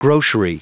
Prononciation du mot grocery en anglais (fichier audio)
Prononciation du mot : grocery